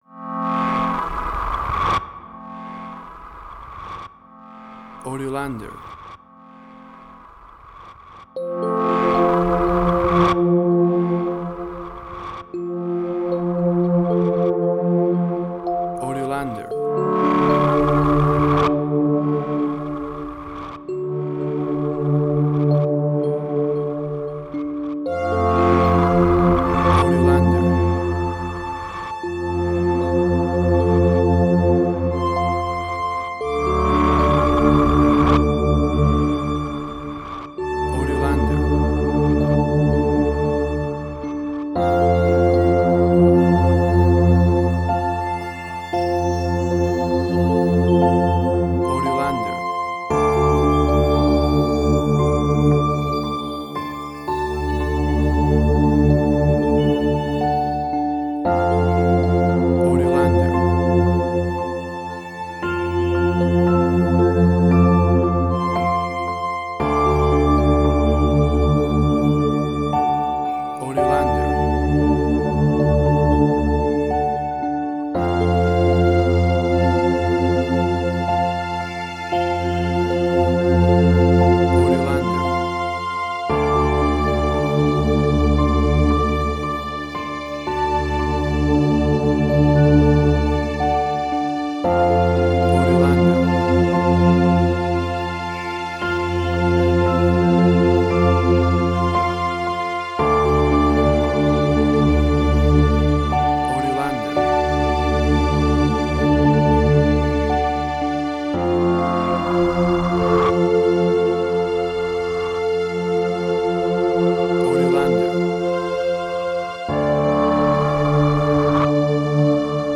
Ambient Strange&Weird